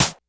Snare_08.wav